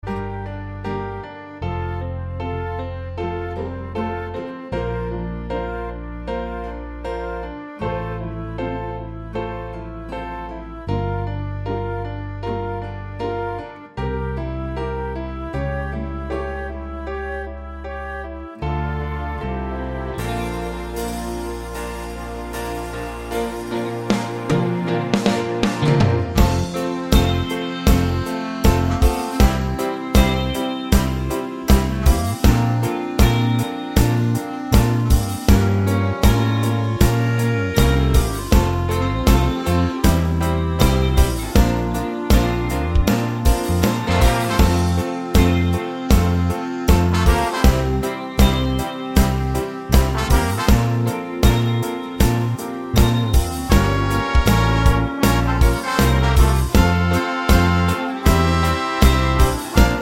for solo singer Musicals 3:39 Buy £1.50